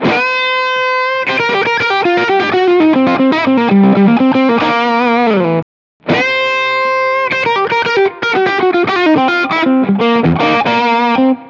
The Ron Ellis set seems far more reactive to picking technique.
Here's a clip contrasting the two bridge pickups: